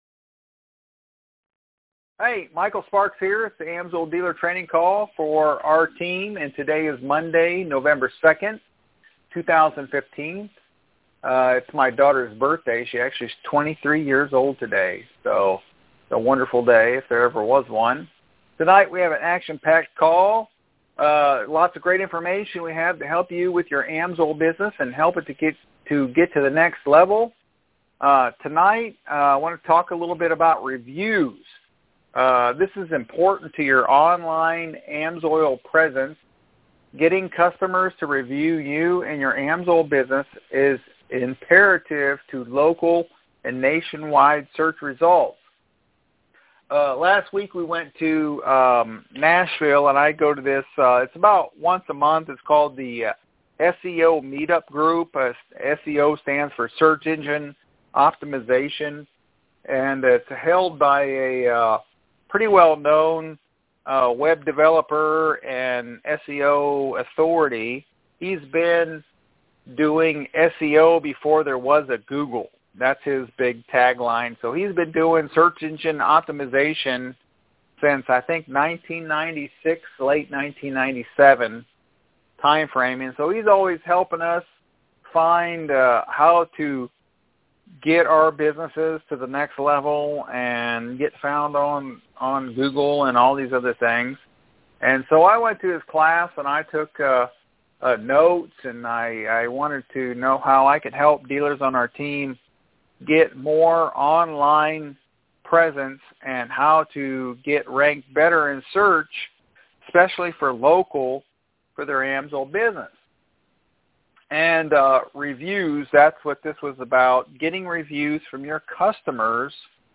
AMSOIL Dealer training call. Listen our weekly call and learn how to build your AMSOIL Business.